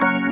Keyboards » Organ 17
描述：Recorded from DB33Protools Organ . 44khz 16bit stereo , without wave chunks.
标签： keyboards organ db33